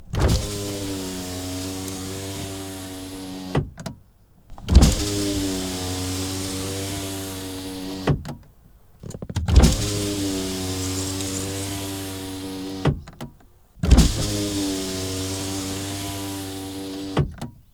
Toyota Corolla Verso 1.6 foley interior window open mono.wav